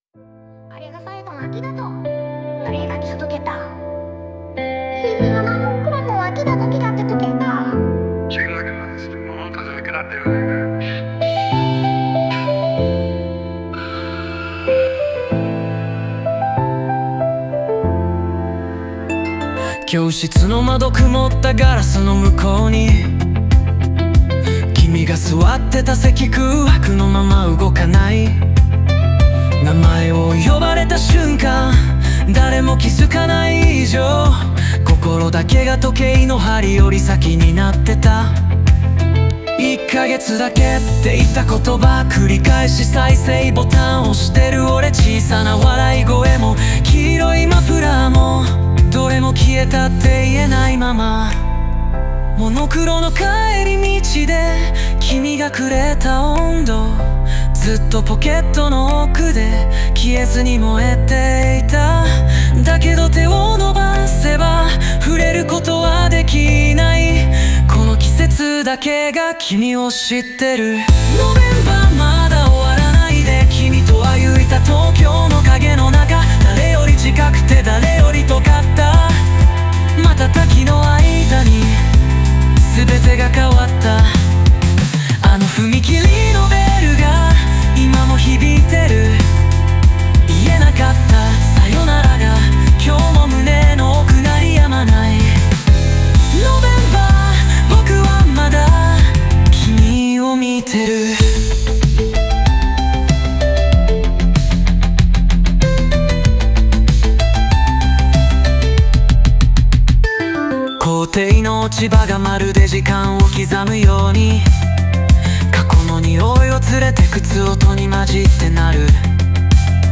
オリジナル曲♪
時間が止まったような感覚とその切なさを強調し、メロディ部分ではその想いが爆発的に広がります。